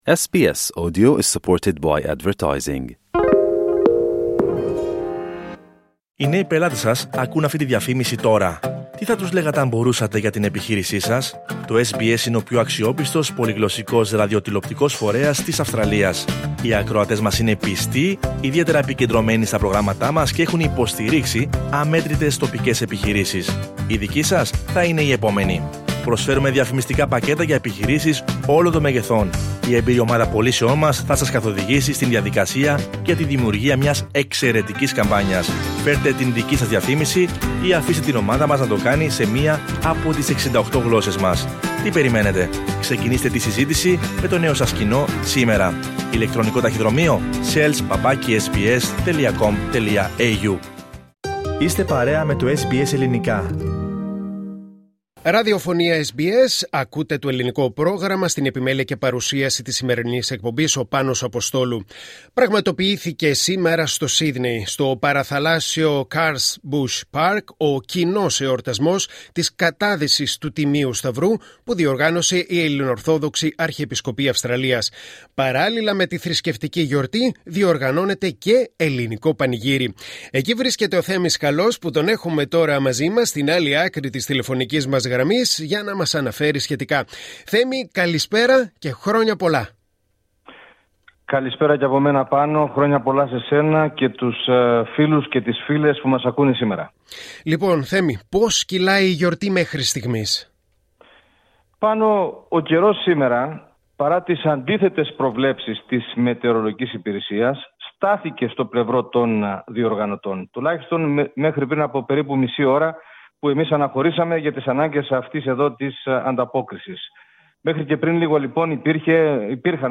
Σύδνεϋ: Χιλιάδες κόσμος στον Αγιασμό των Υδάτων και στο ελληνικό πανηγύρι